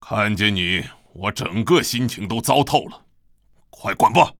文件 文件历史 文件用途 全域文件用途 Hartz_fw_03.ogg （Ogg Vorbis声音文件，长度4.4秒，104 kbps，文件大小：56 KB） 源地址:地下城与勇士游戏语音 文件历史 点击某个日期/时间查看对应时刻的文件。